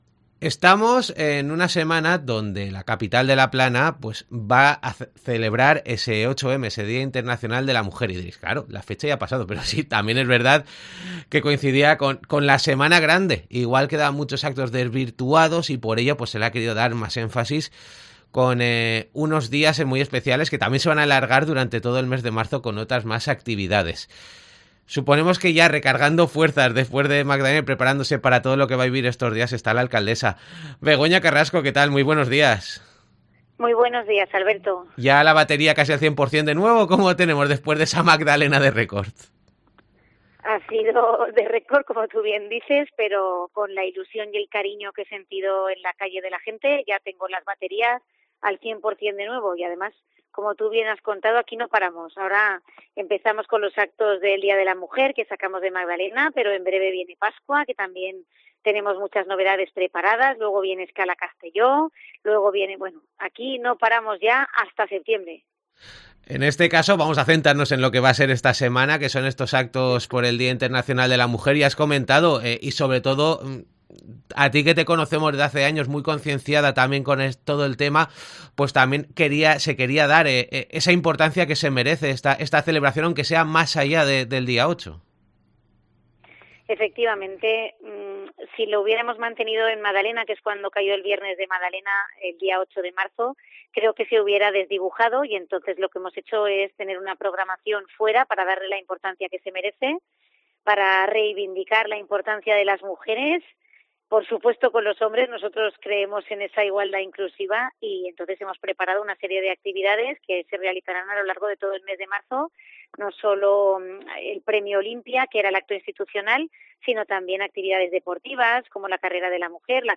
Los actos por el 8-M y otros temas de actualidad con la alcaldesa de Castellón, Begoña Carrasco